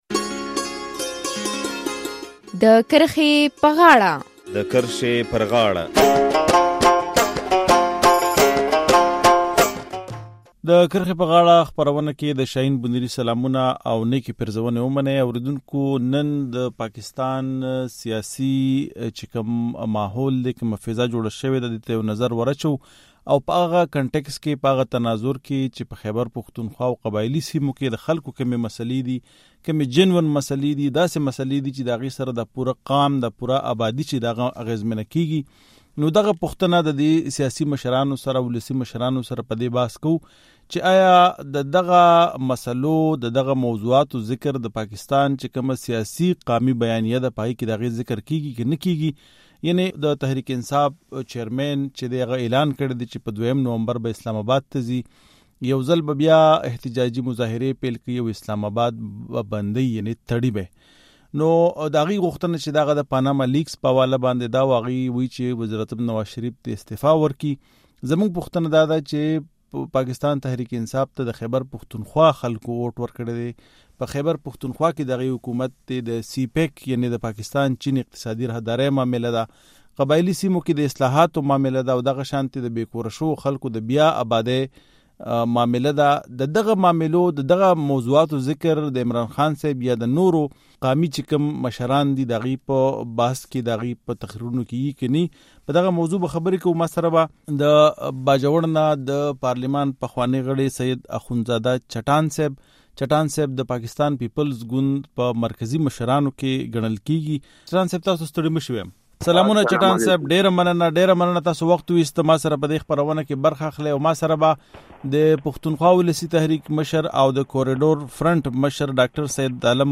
احتجاج د هر سیاسي ګوند جمهوري حق دی خو پوښتنه داده چې د پښتونخوا خلکو تحریک انصاف له رای ورکړي دي نو آیا عمران خان د صوبې د ولس د ستونزو او غوښتنو خبره هم کوي که نا؟ د کرښې پر غاړه کې په خپرونه کې په دغه موضوع بحث کوو.